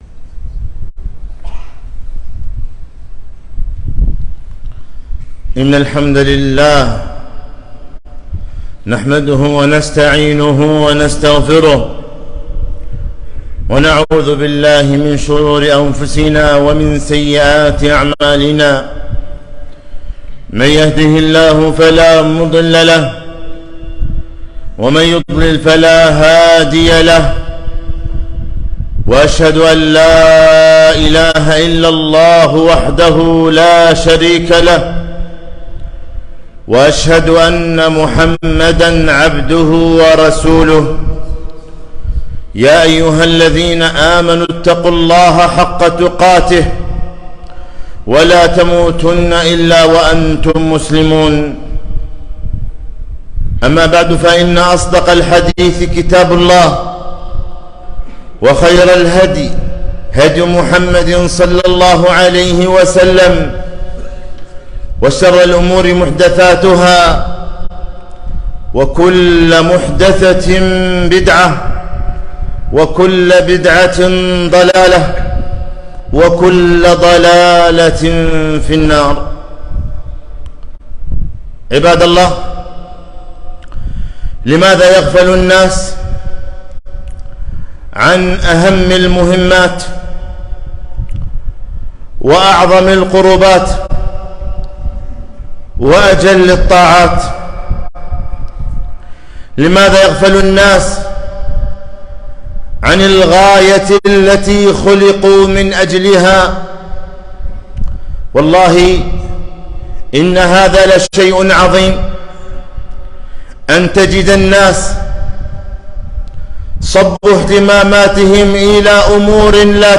خطبة - التوحيد أولاً يا عباد الله